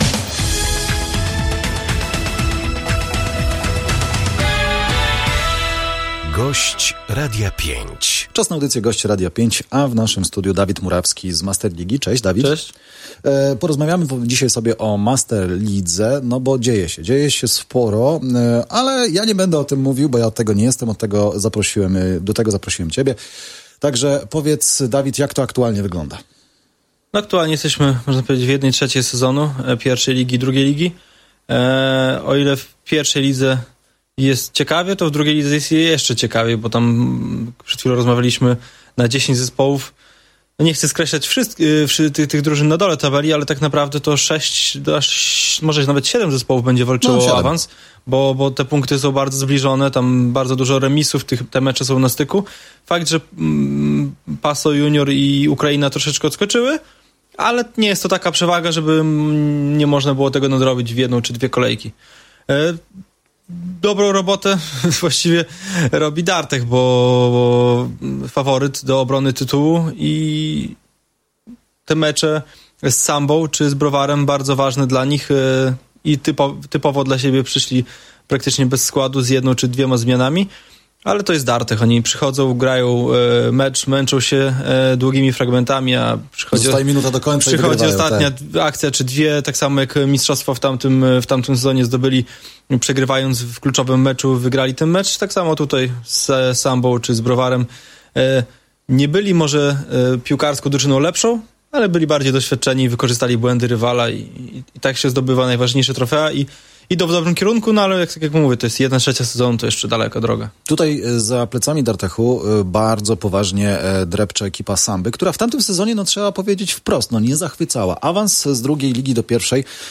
01-10-gosc-z-jinglami-do-powtorki.mp3